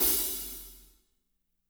-18  CHH H-R.wav